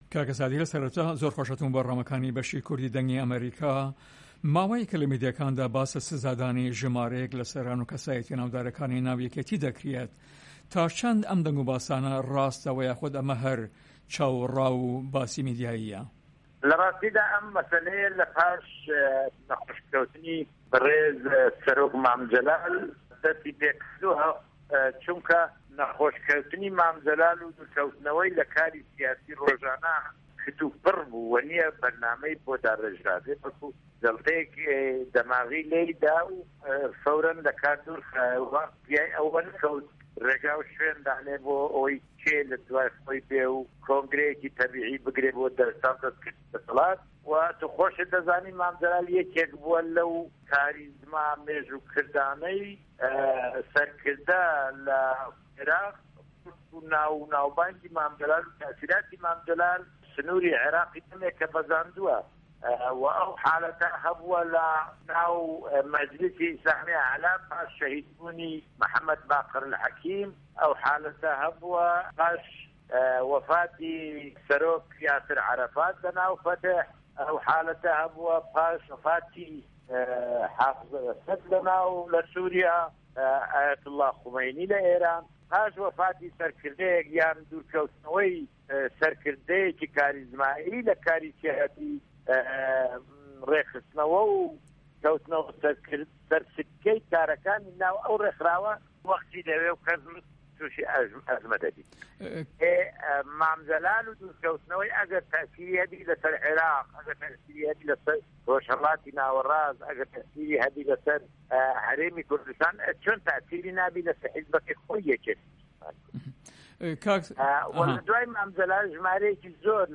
Interview with Sadi Pire